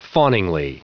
Prononciation audio / Fichier audio de FAWNINGLY en anglais
Prononciation du mot : fawningly